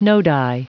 Prononciation du mot nodi en anglais (fichier audio)
Prononciation du mot : nodi